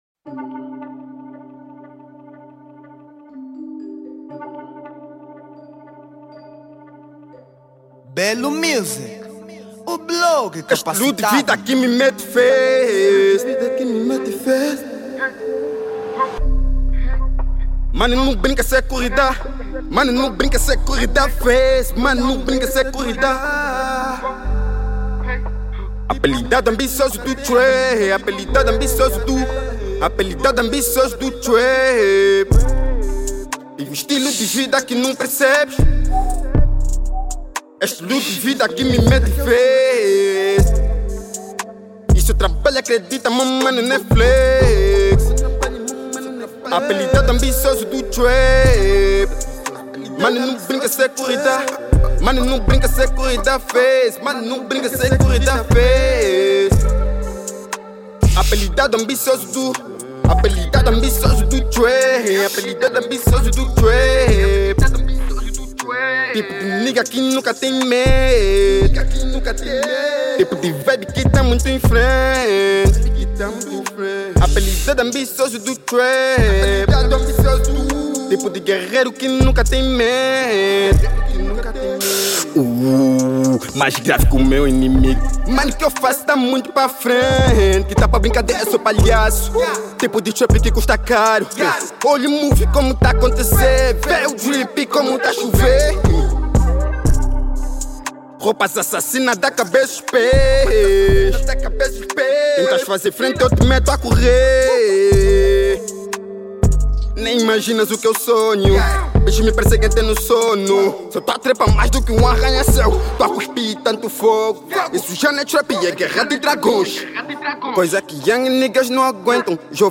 Género : Trap